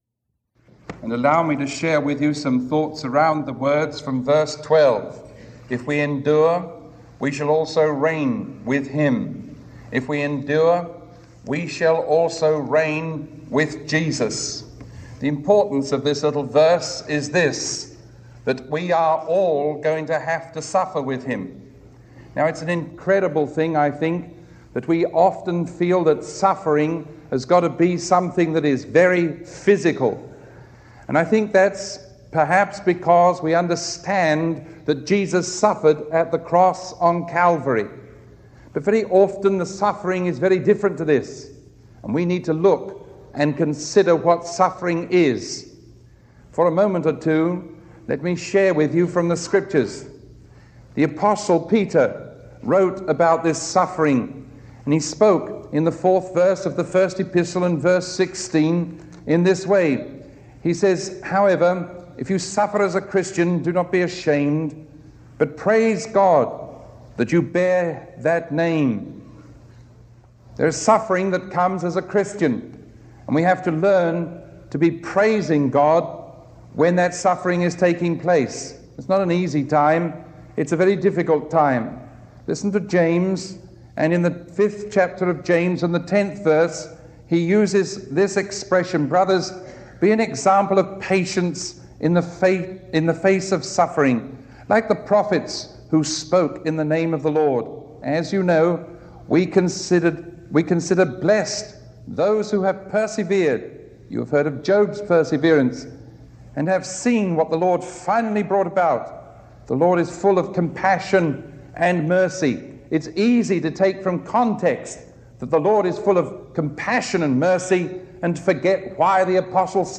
Sermon 0890A recorded on May 20